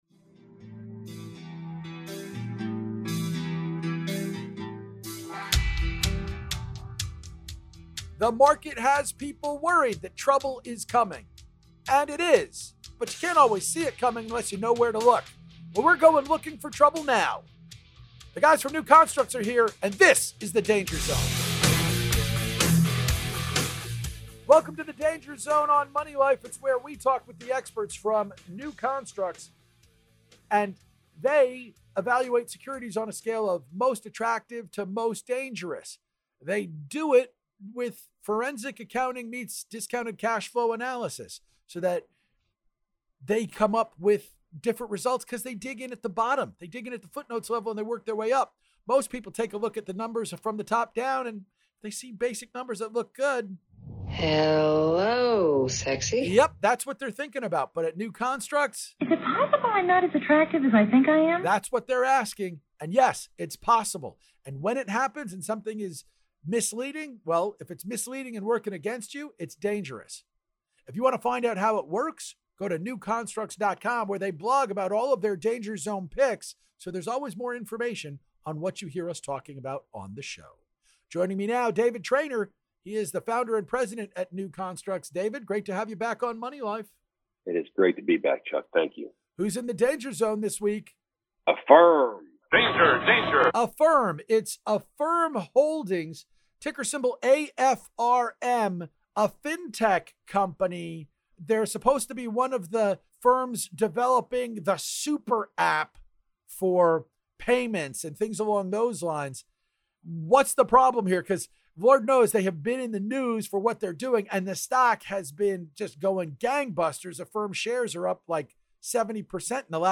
Danger Zone interview